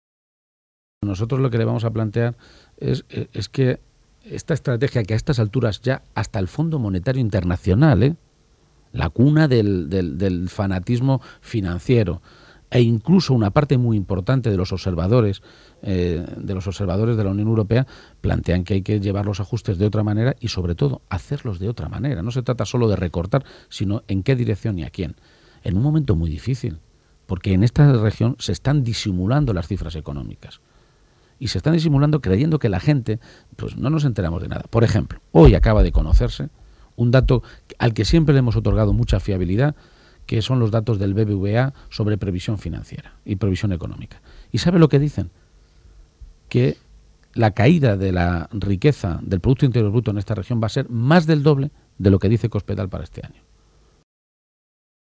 Emiliano García-Page durante la entrevista que se le ha realizado en la Cadena SER
Cortes de audio de la rueda de prensa